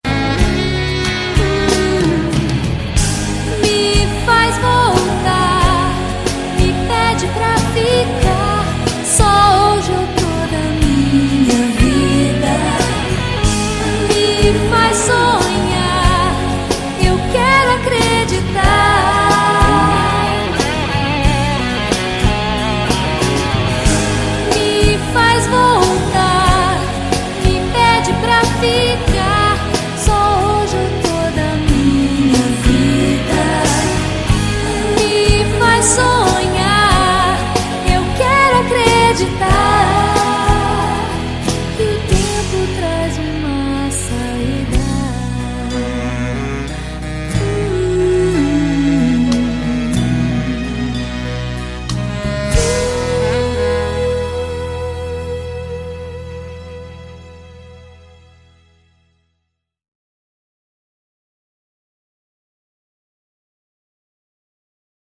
красивые
женский вокал
романтичные